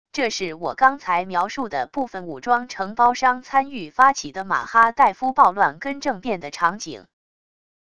这是我刚才描述的部分武装承包商参与发起的马哈代夫暴乱跟政变的场景wav音频